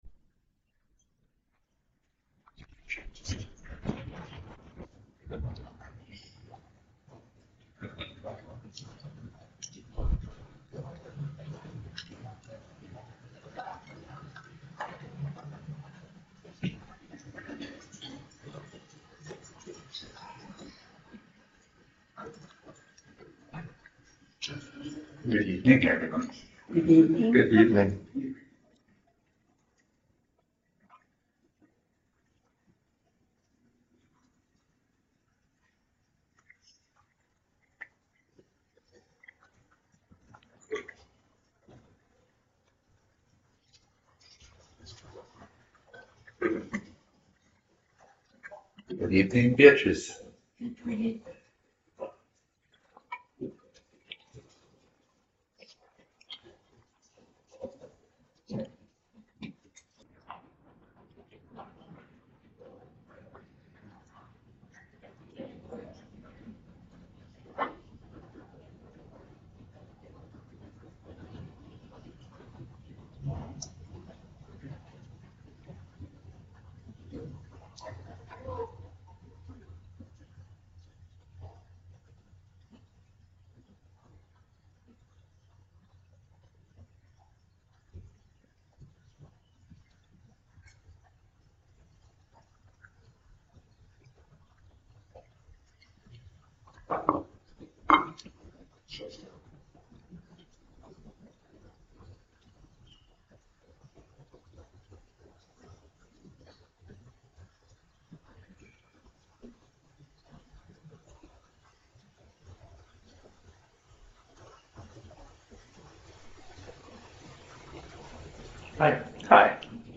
Edit talk Download audio (mp3) Download original audio Listen to original audio * Audio files are processed to reduce background noise, and provide (much) better compression.